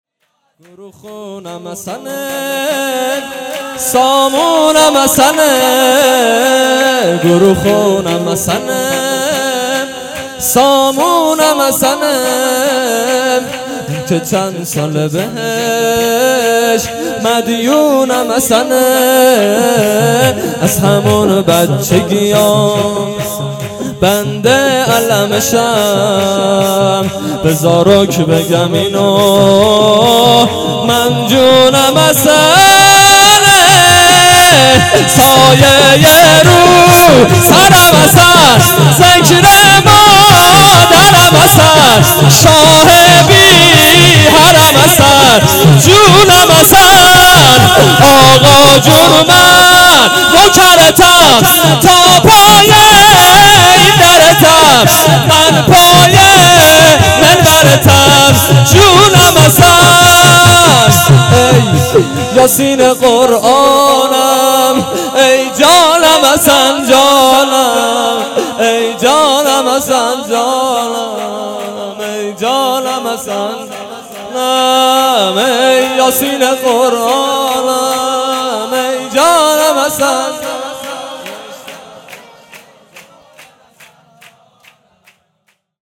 جشن ولادت حضرت معصومه سلام الله علیها